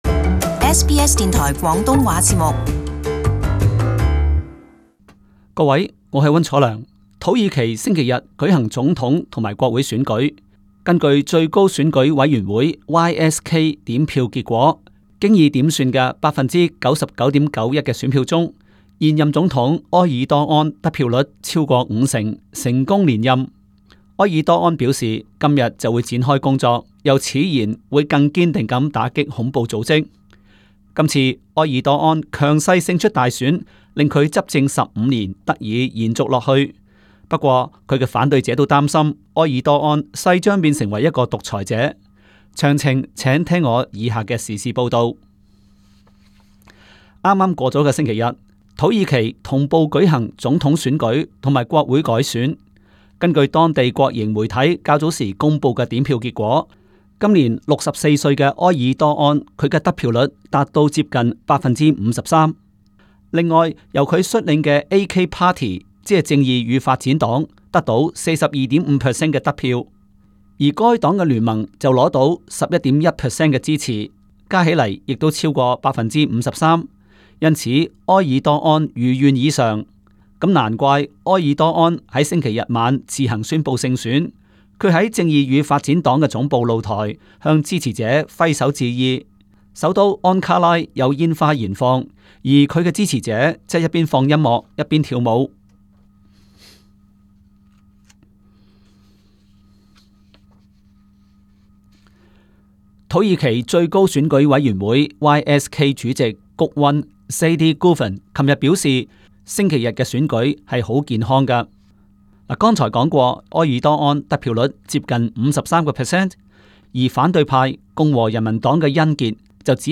【时事报导】埃尔多安成爲土耳其超级总统